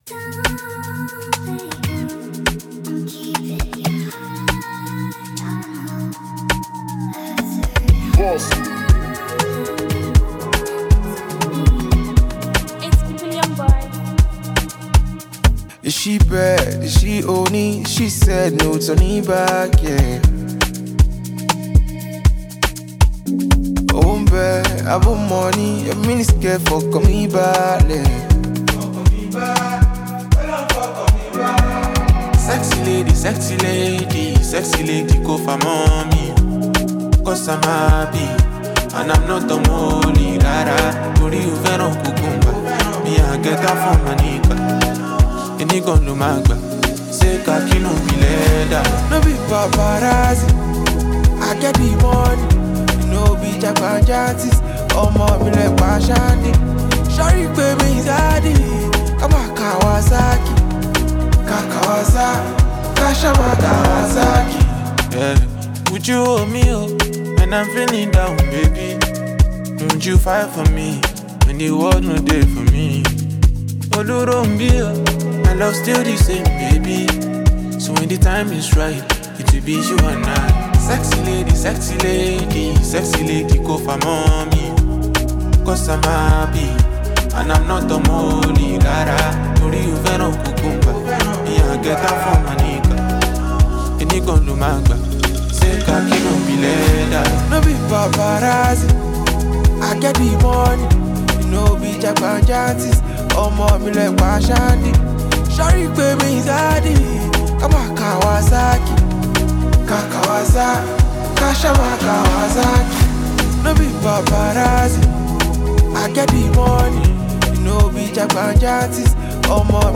adds a melodic and soulful touch
with many praising its catchy hook and replay value.